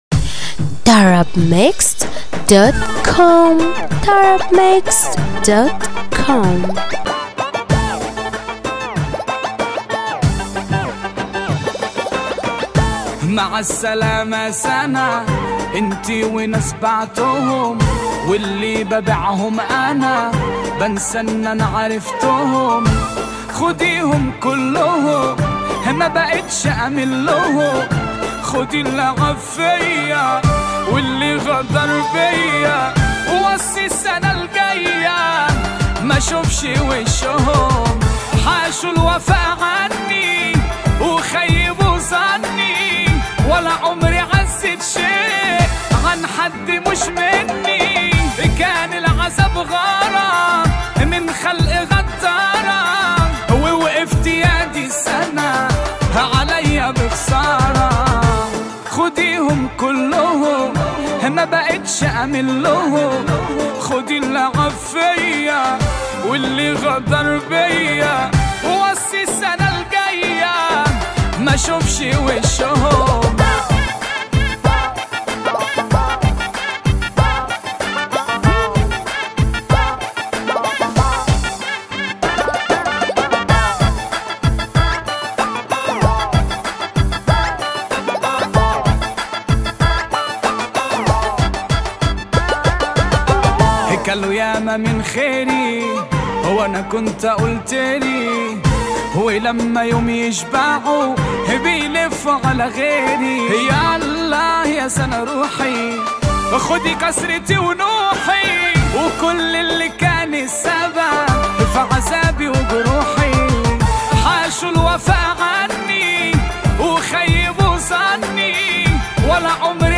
النوع : festival